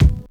keys_24.wav